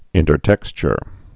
(ĭntər-tĕkschər)